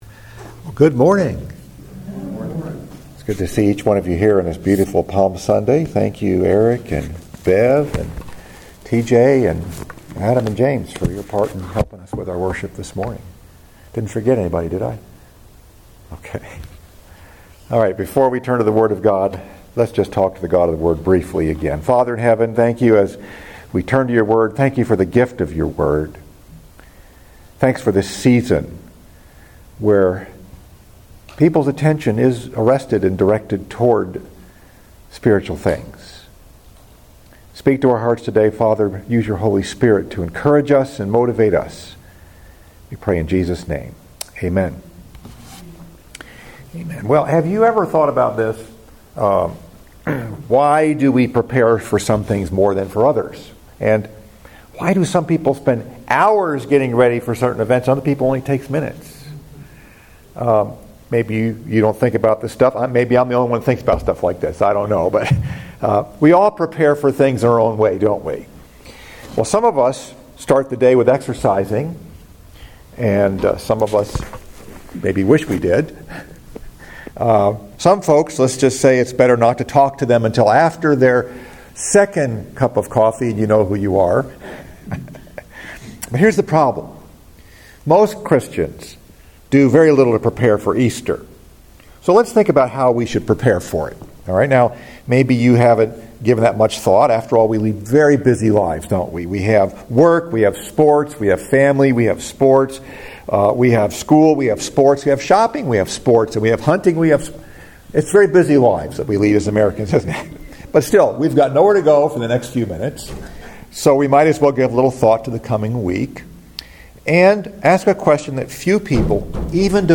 Message: “Preparing for Easter” Scripture: Luke 19 & Matthew 21 Sixth Sunday of Lent – Palm Sunday